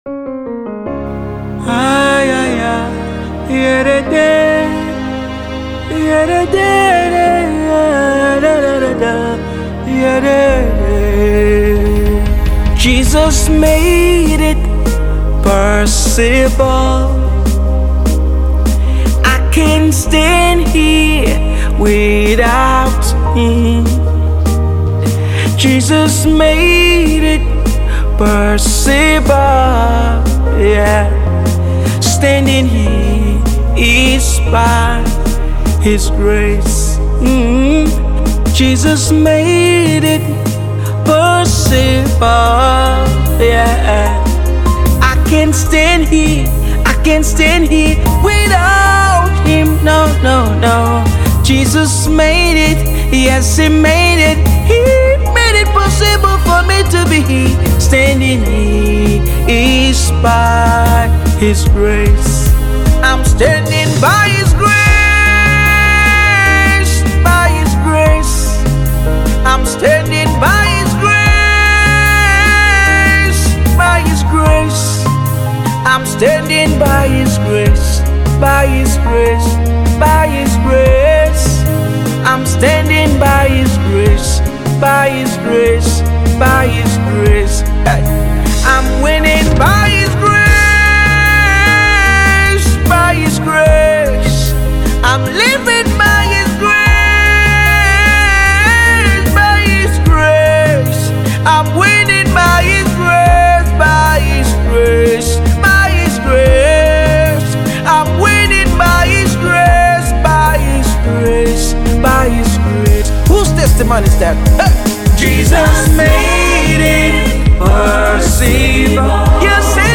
contemporary gospel musician.